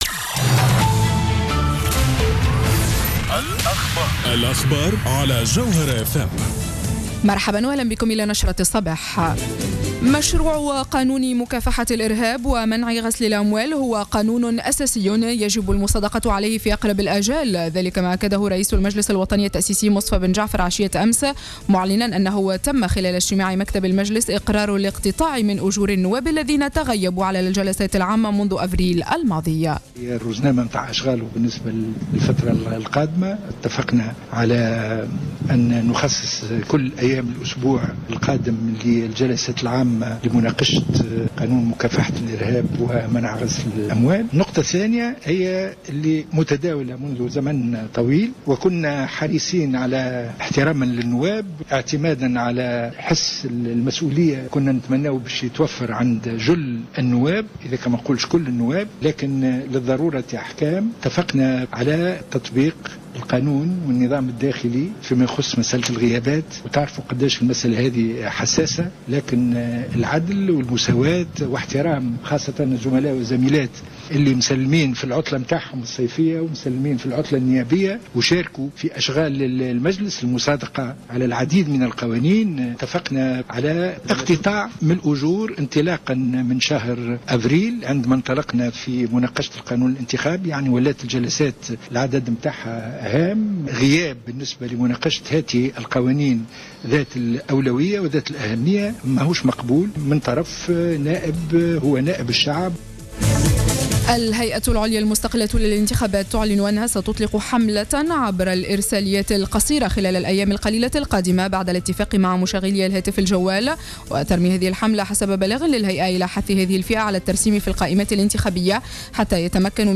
نشرة أخبار السابعة صباحا ليوم السبت 16-08-14